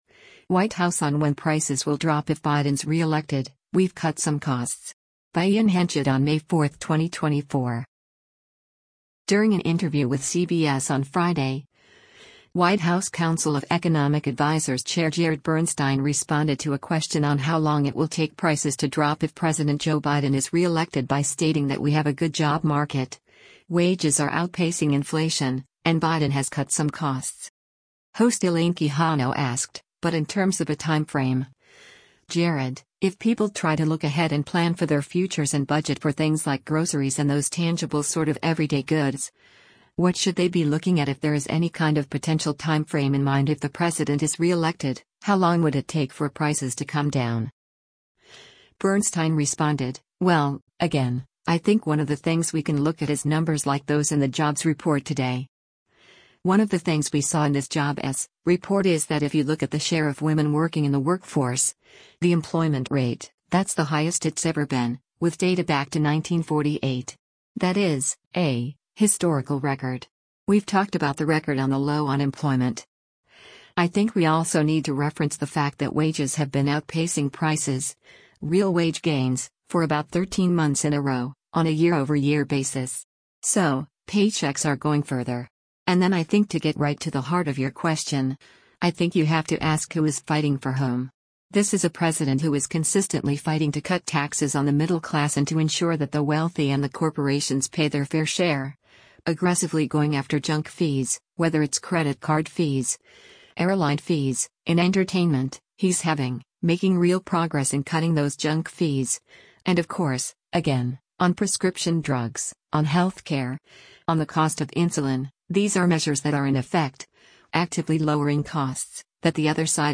During an interview with CBS on Friday, White House Council of Economic Advisers Chair Jared Bernstein responded to a question on how long it will take prices to drop if President Joe Biden is re-elected by stating that we have a good job market, wages are outpacing inflation, and Biden has cut some costs.